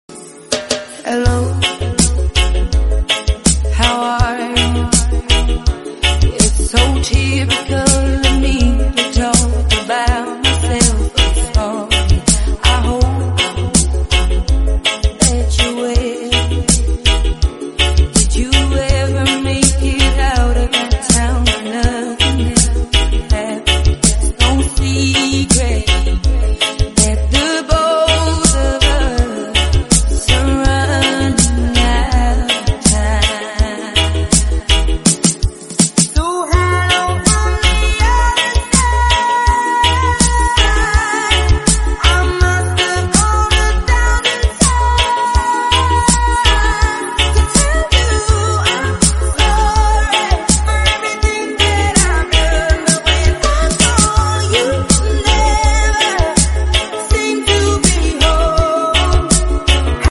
Reggae Remix